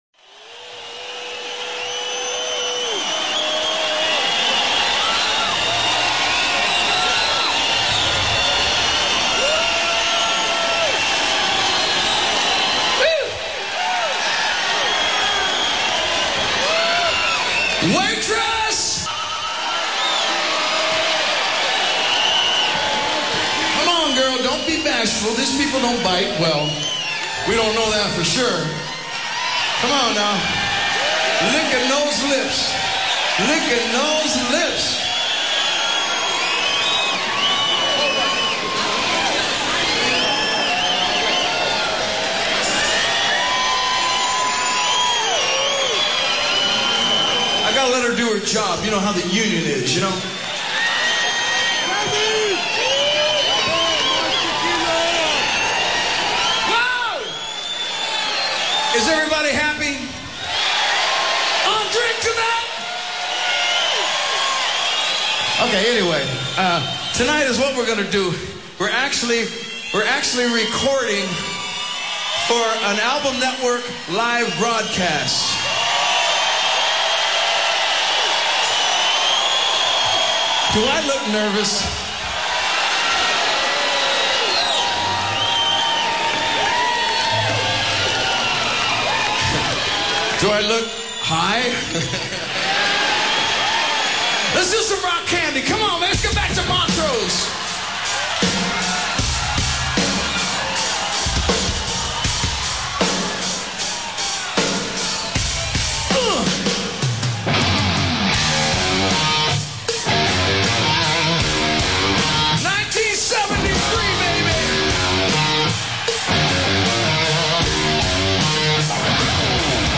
"In Concert"
Live at the Universal Amphitheatre - Los Angeles